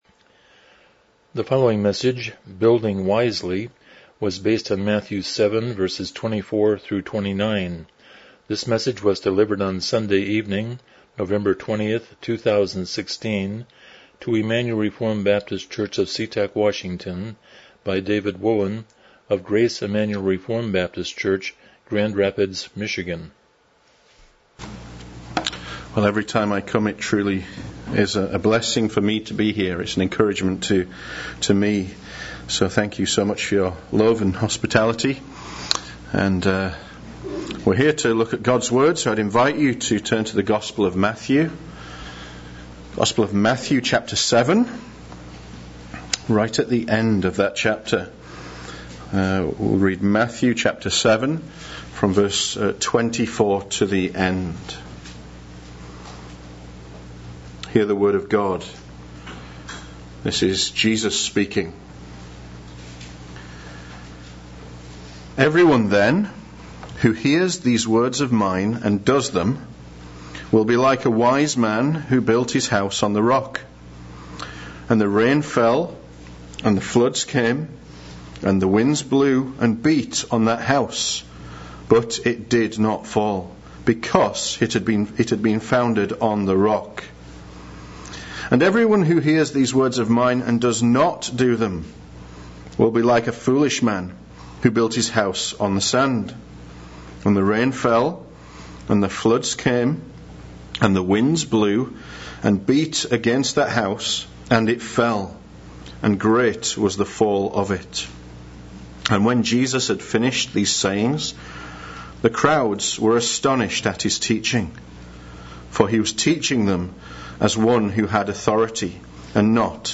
Miscellaneous Passage: Matthew 7:24-29 Service Type: Evening Worship « Contending for the Faith